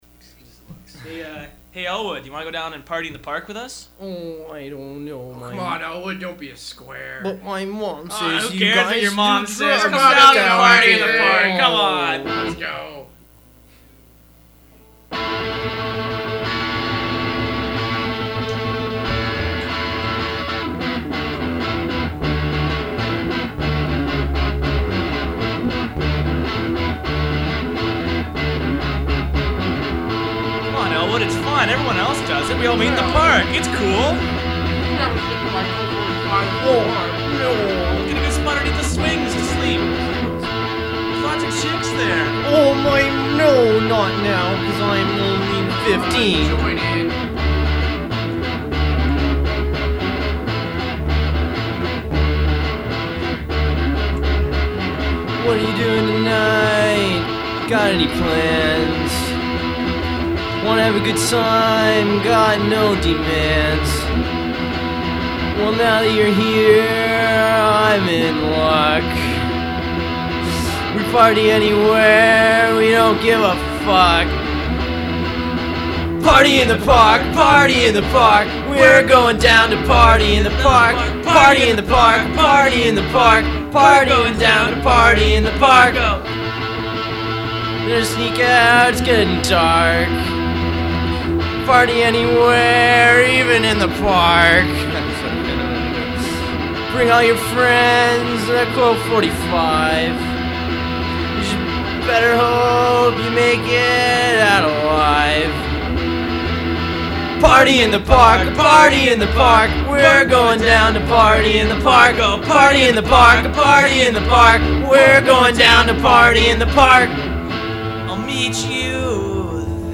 Click above to hear the "drunk off one beer cut" of Party in the Park